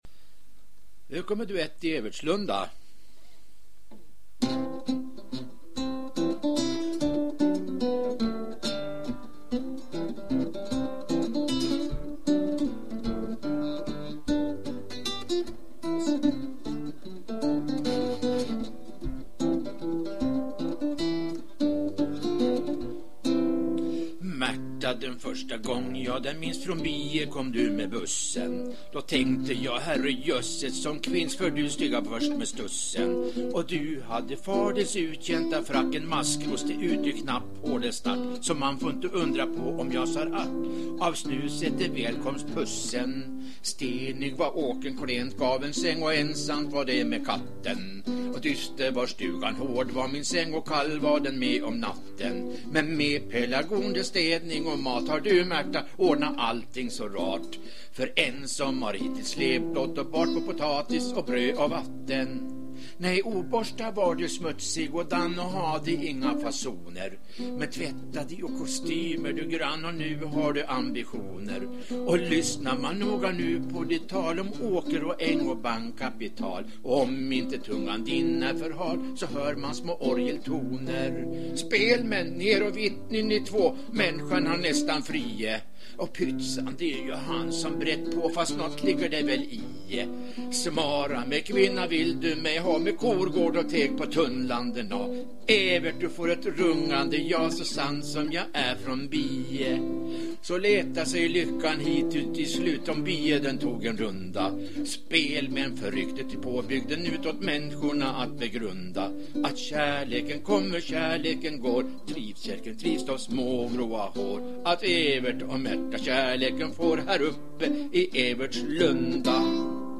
duett i evertslunda.mp3